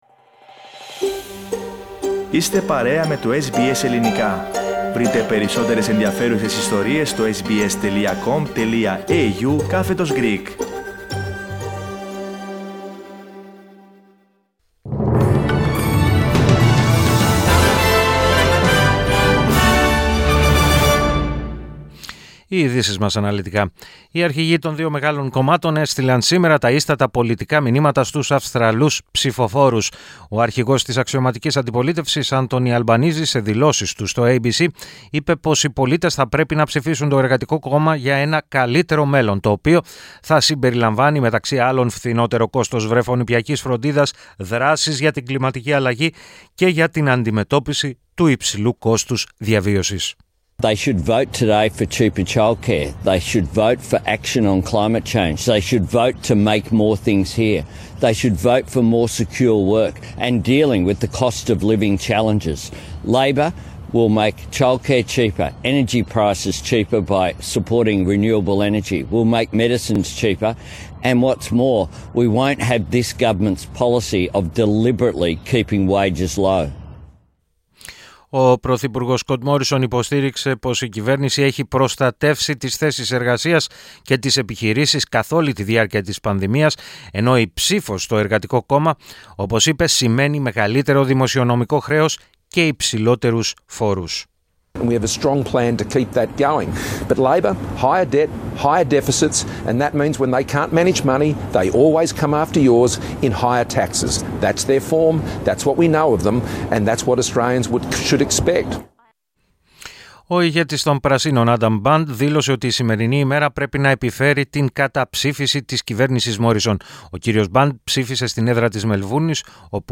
Ειδήσεις 21.05.22
News in Greek. Source: SBS Radio